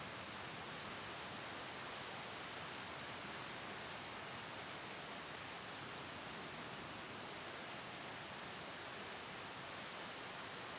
Weather Afternoon Medium Rain ST450 01_ambiX.wav